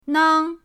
nang1.mp3